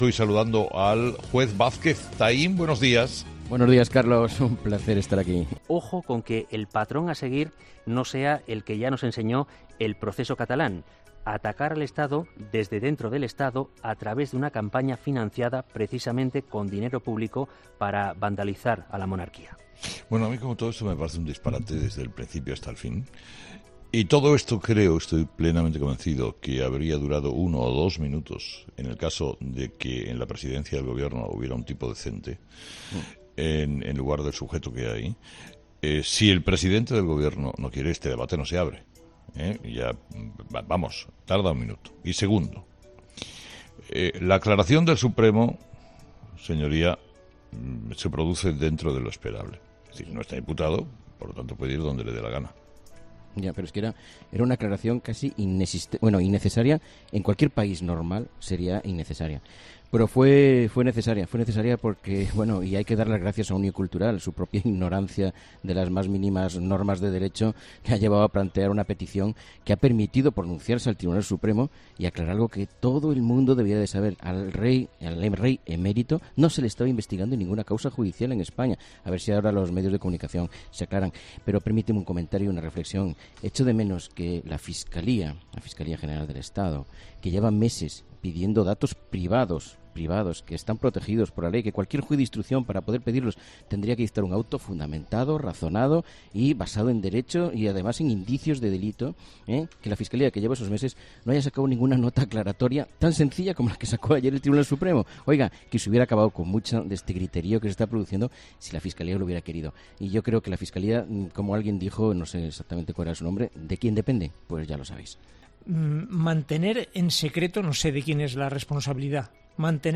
Entrevistado: "José Antonio Vázquez Taín"